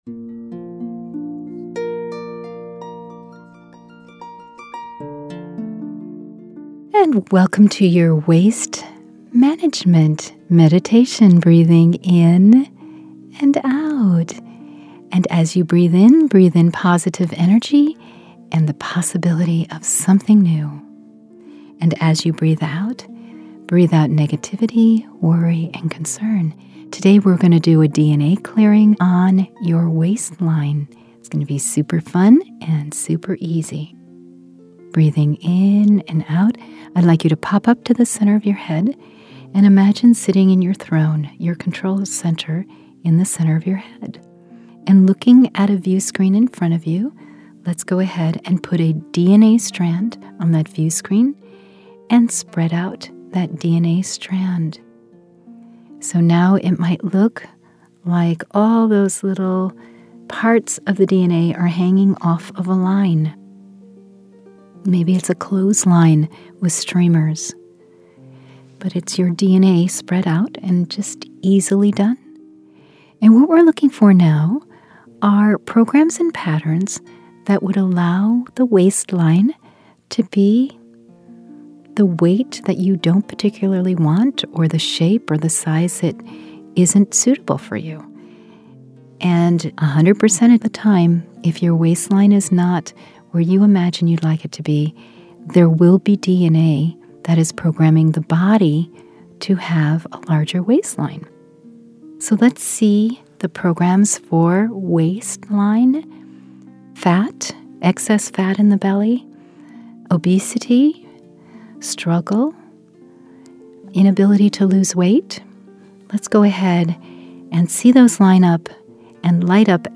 Meditation Bundle